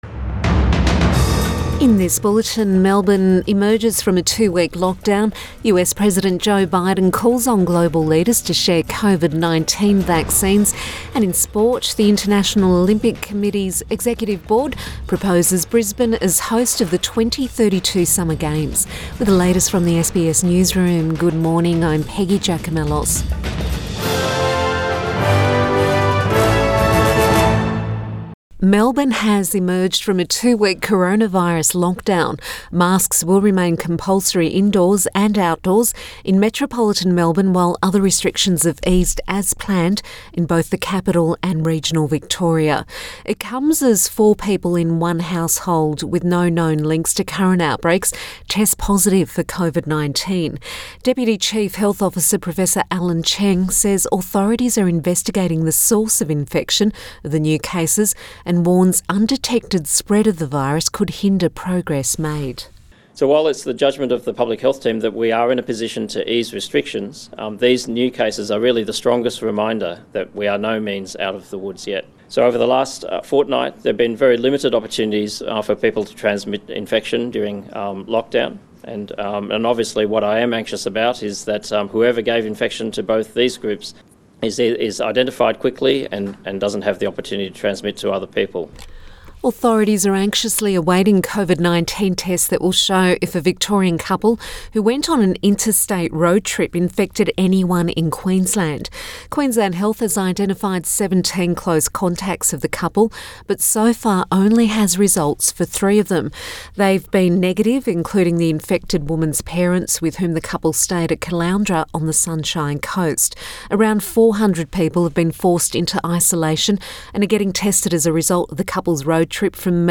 AM bulletin 11 June 2021